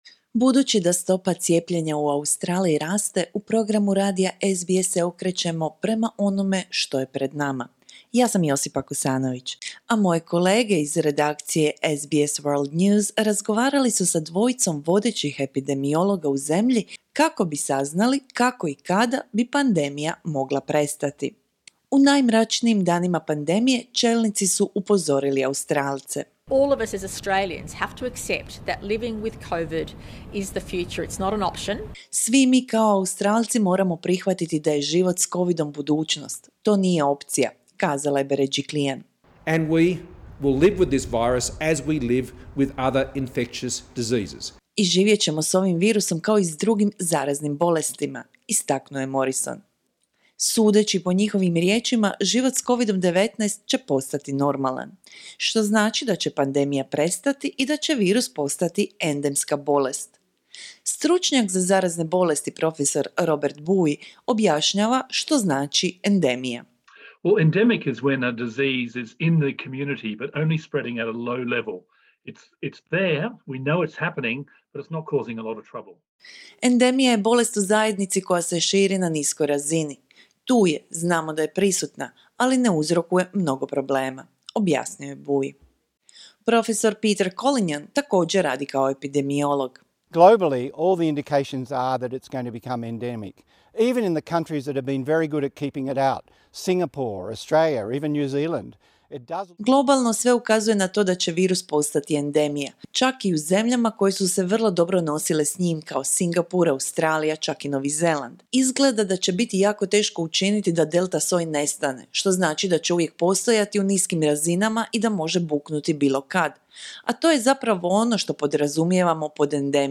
Budući da stopa cijepljenja u Australiji raste, razgovor se kreće prema onome što je pred nama. SBS World News razgovarao je s dvojicom vodećih epidemiologa u zemlji kako bi saznali kako i kada bi pandemija mogla prestati.